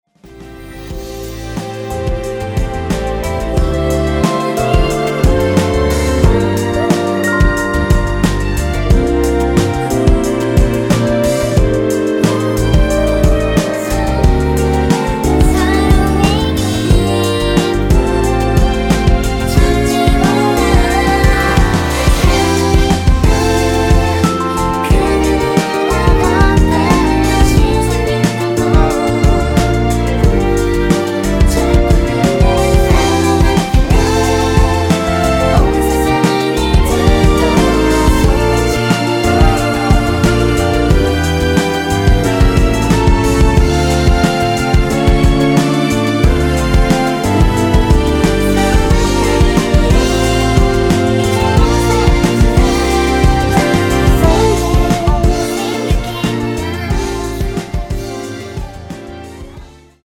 원키에서(+2)올린 멜로디와 코러스 포함된 MR입니다.(미리듣기 확인)
앨범 | O.S.T
앞부분30초, 뒷부분30초씩 편집해서 올려 드리고 있습니다.